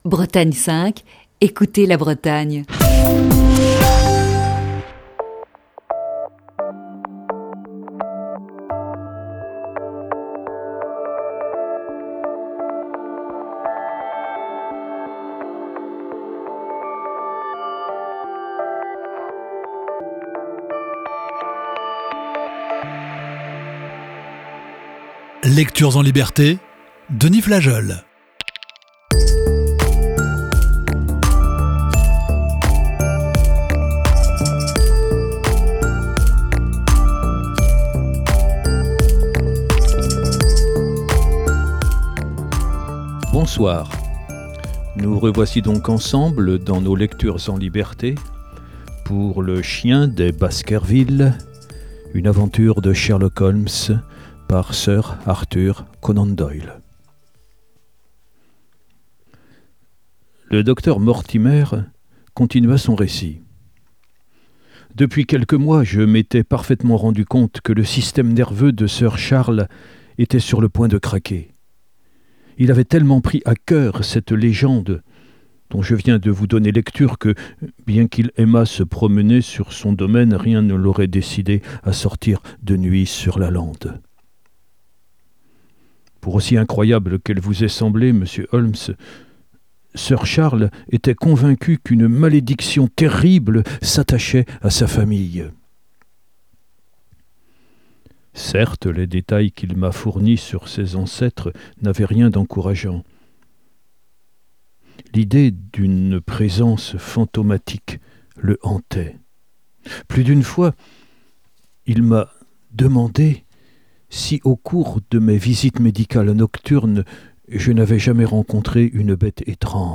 Émission du 12 janvier 2021.